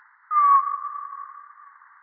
V létě jsem se po večerech toulal se speciálně upraveným magnetofonem a natáčel.
Zpomalený netopýří výkřik je však možné studovat mnohem
Všechny zde uvedené zvukové ukázky byly zpomaleny v poměru 1:20.